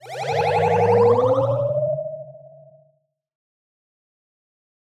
Retro Vehicle SciFi 01.wav